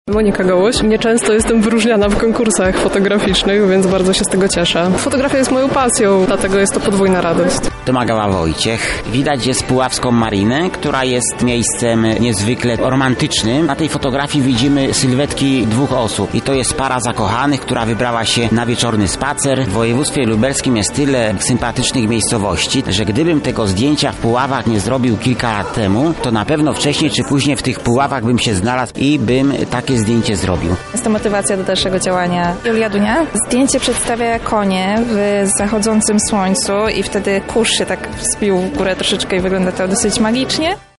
Więcej o wydarzeniu mówią laureaci: